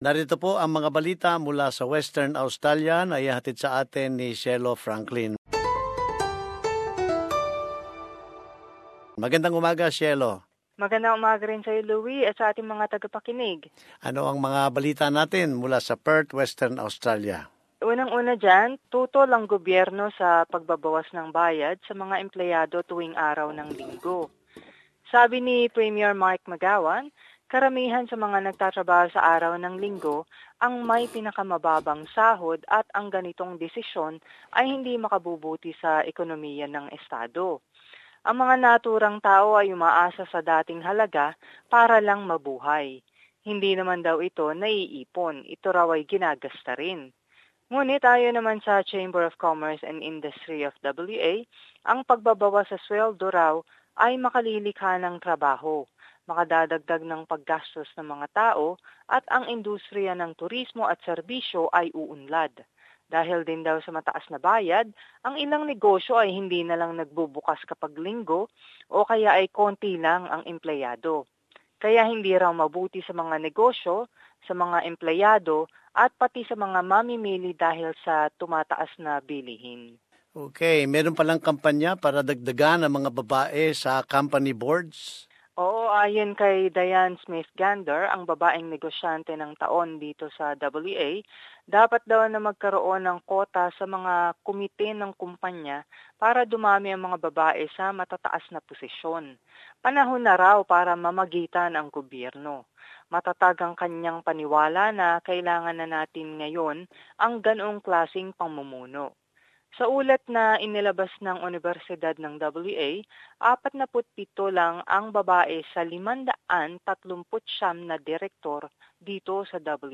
Bahagi ng mga balita mula Western Australia